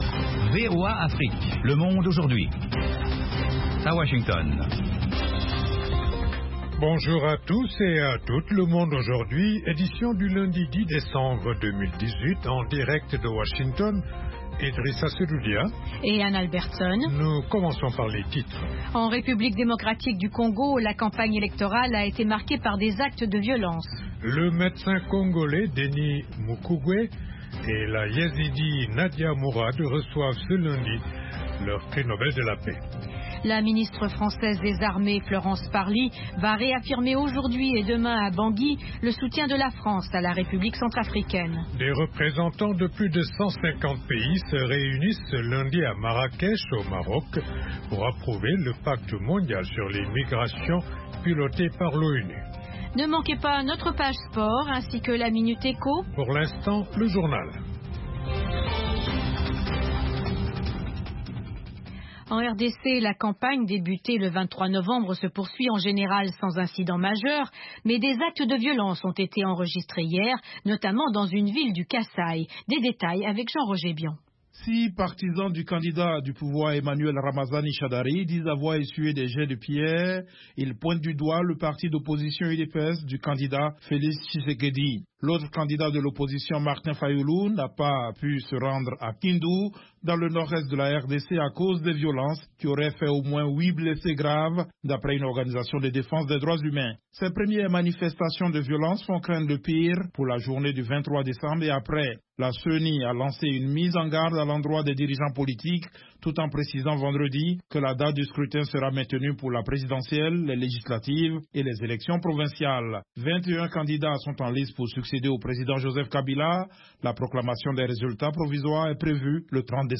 Interviews, reportages de nos envoyés spéciaux et de nos correspondants, dossiers, débats avec les principaux acteurs de la vie politique et de la société civile. Le Monde Aujourd'hui vous offre du lundi au vendredi une synthèse des principaux développementsdans la région.